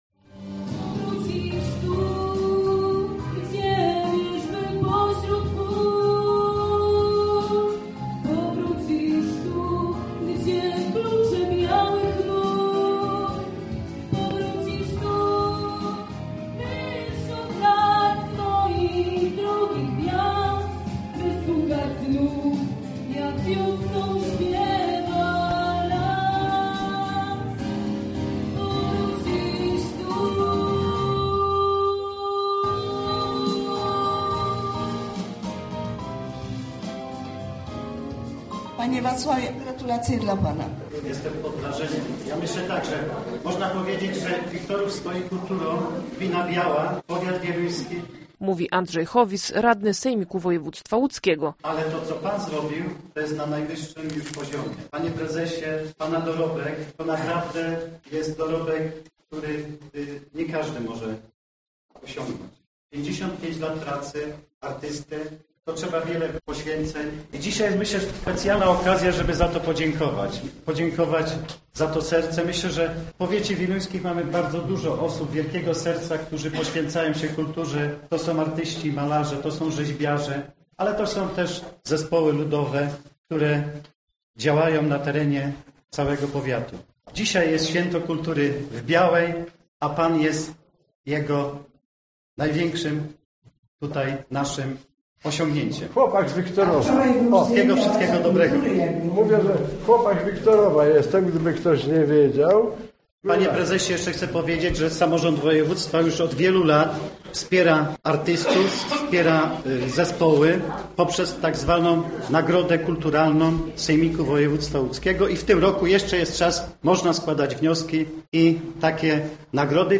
Relacja z tego spotkania do posłuchania tutaj: